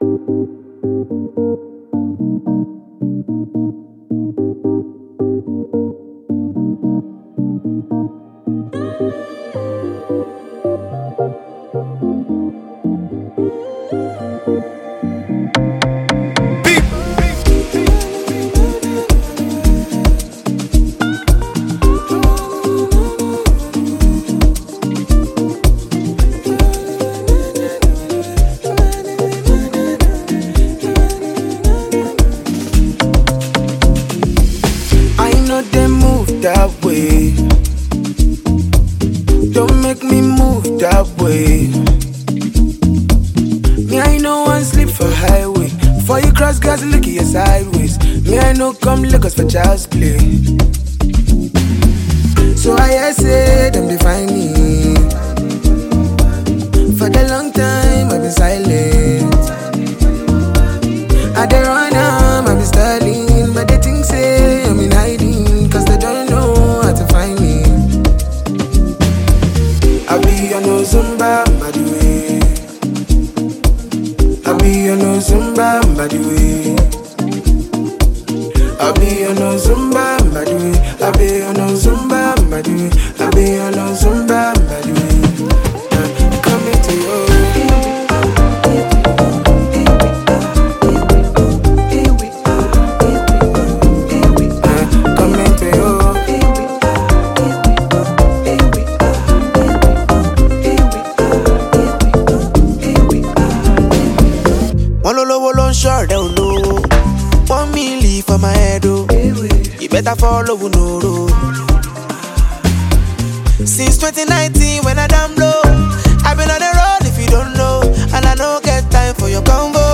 Afro-beats vibes